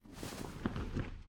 catch_air_2.ogg